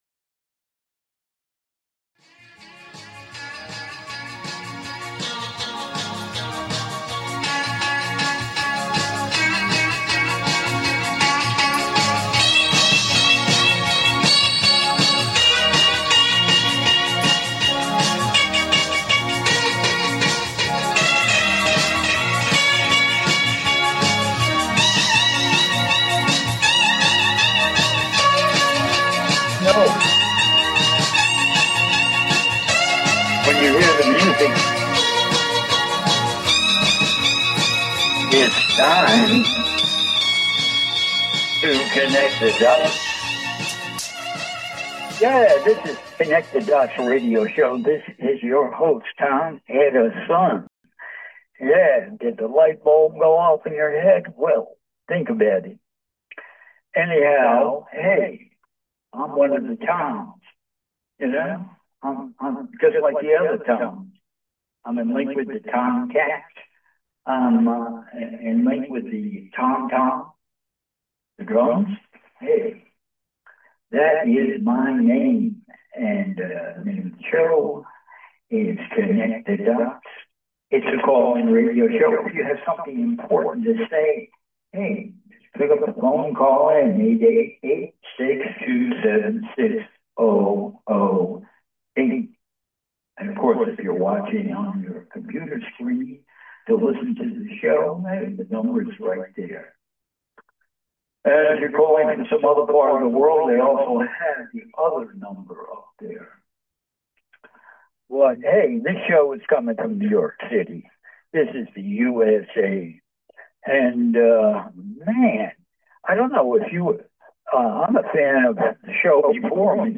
Talk Show Episode, Audio Podcast, Connect The Dots and Global Conspiracies, Civic Reform, and Metaphysical Awakening on , show guests , about global conspiracies,Civic Reform,Metaphysical Awakening,Hidden Conflict,Systemic Control,Health Skepticism,Political Critique,the Referendum Model,Galactic Confederation, categorized as Earth & Space,Entertainment,News,Physics & Metaphysics,Politics & Government,Society and Culture,Spiritual,Access Consciousness,Theory & Conspiracy